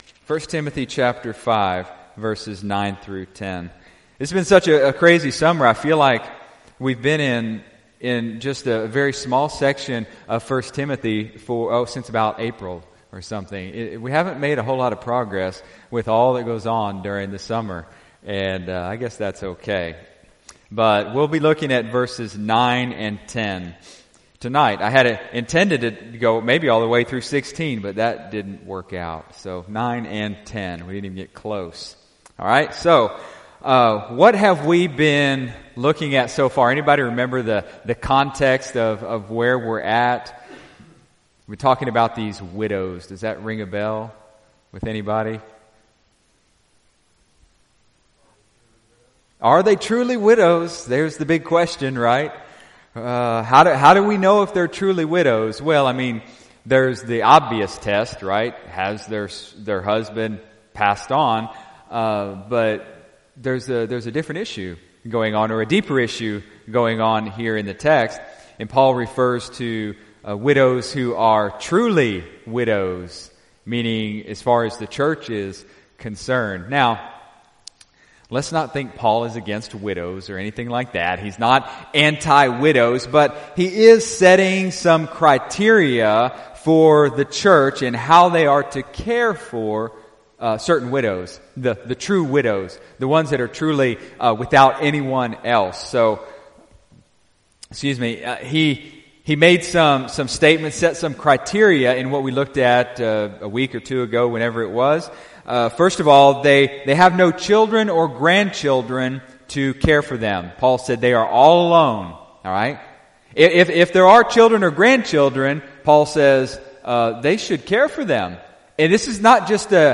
1 Timothy | Sermon Series | Dallasburg Baptist Church
February 9, 2014 (Sunday Morning Service)